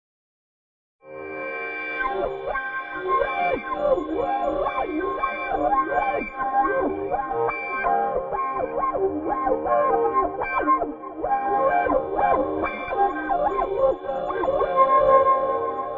电影般的时刻 短暂而突然 " 尖锐而遥远 0 38mi
描述：一个合成的嘶嘶声被一个尖锐的金属声分层，并带有长混响。
Tag: 尖锐 混响 尖锐 嘶嘶声 远处 时刻 金属 电影 电影